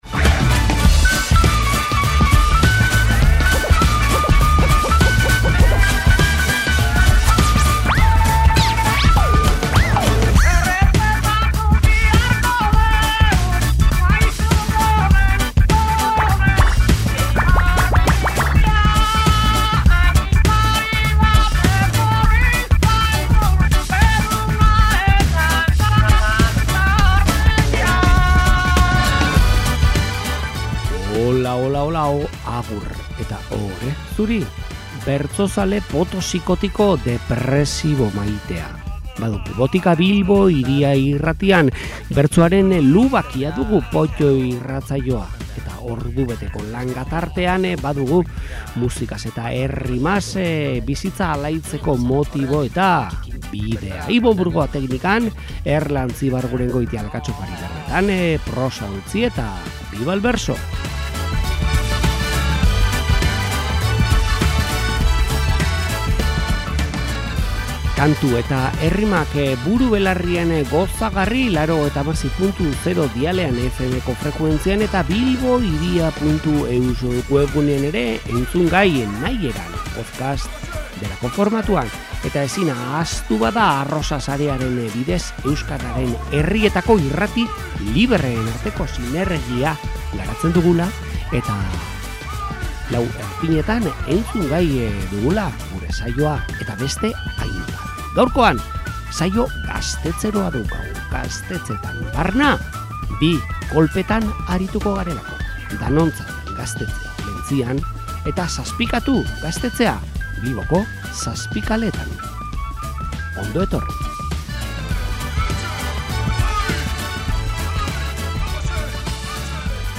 Plentzia eta Bilbo, 2025eko abendua eta 2024ko aspaldiko saioa. Bertsolari gazteak eta ez hain gazteak